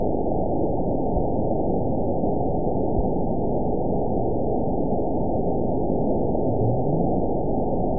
event 911106 date 02/10/22 time 09:14:20 GMT (3 years, 3 months ago) score 9.59 location TSS-AB01 detected by nrw target species NRW annotations +NRW Spectrogram: Frequency (kHz) vs. Time (s) audio not available .wav